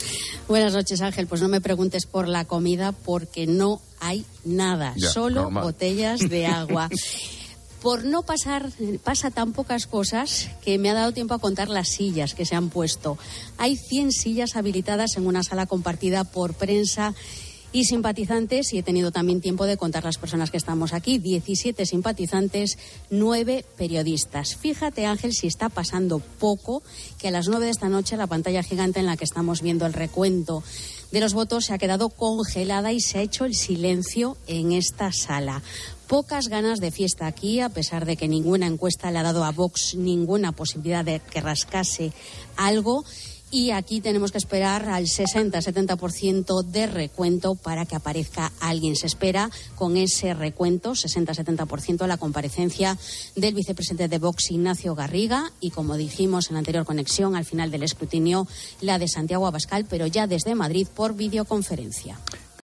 Ambiente de la noche electoral desde Vox Galicia